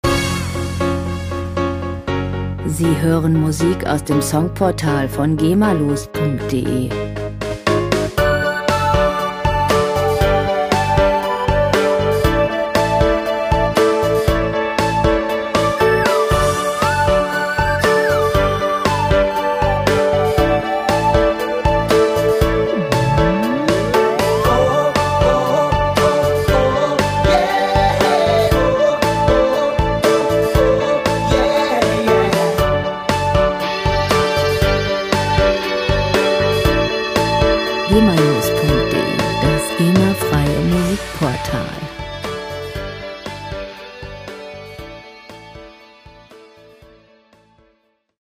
Werbemusik - Lifestyle
Musikstil: Pop Ballad
Tempo: 118 bpm
Tonart: C-Dur/D-Dur
Charakter: verspielt, glücklich
Instrumentierung: Piano, Streicher, Gitarre, Schlagzeug